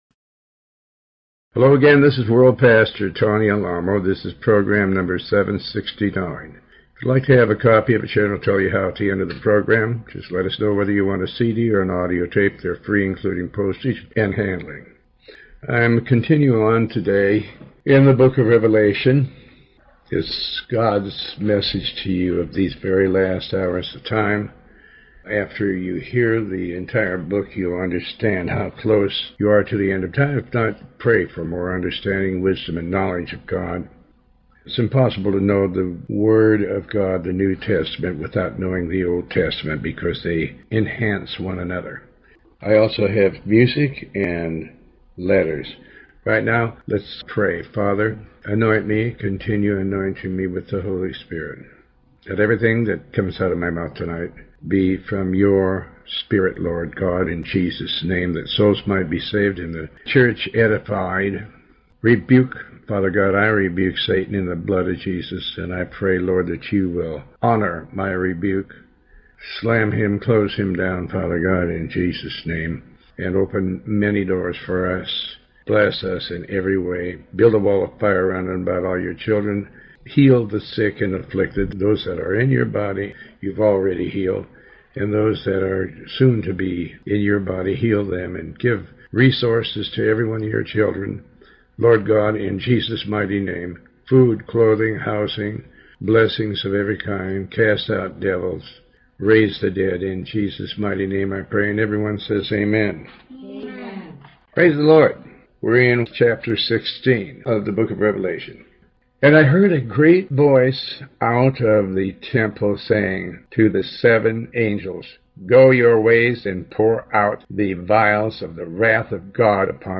Pastor Tony Alamo reads and comments on the book of revelation Chapter 14.